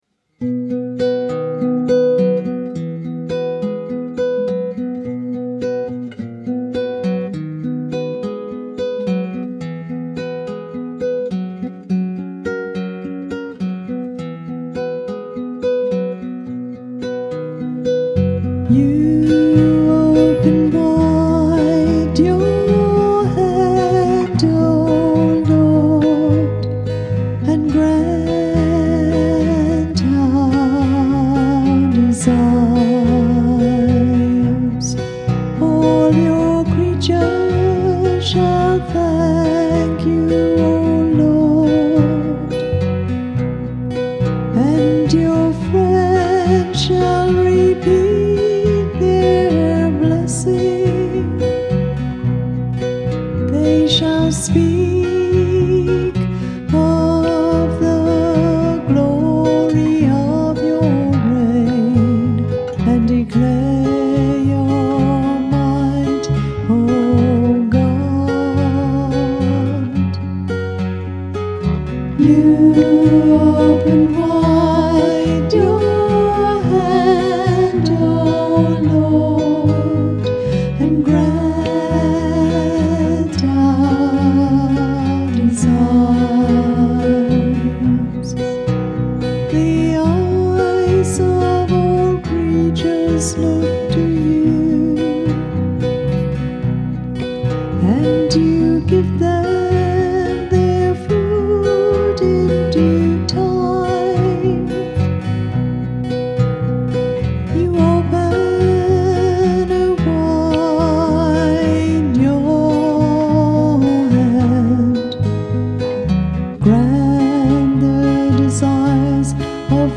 Music by the Choir of Our Lady of the Rosary RC Church, Verdun, St. John, Barbados.